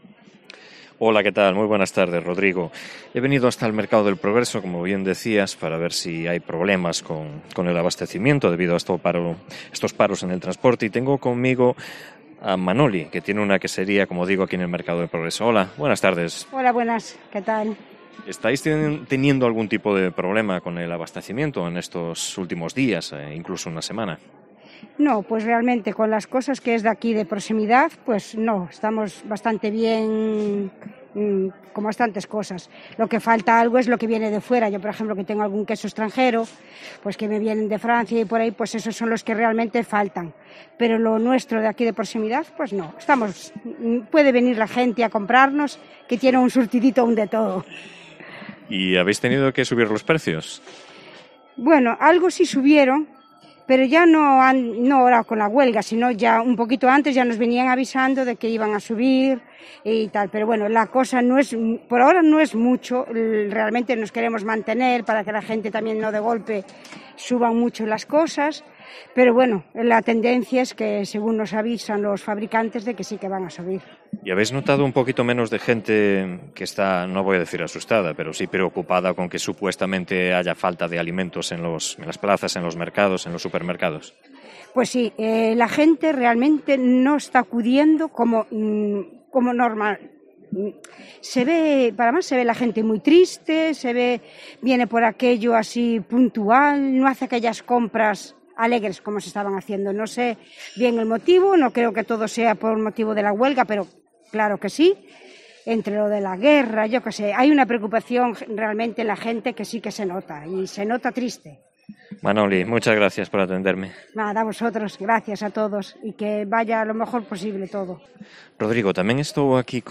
entrevistó
ambas placeras, que se quejan de falta de clientela en las últimas jornadas